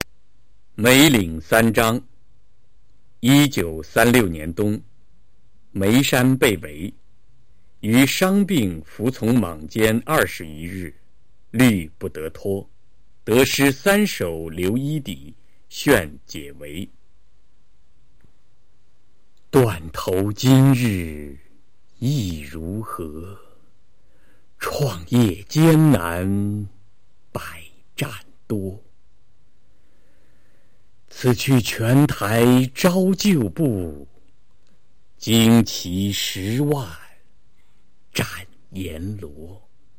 九年级语文下册 2《梅岭三章》男声高清朗读（音频素材）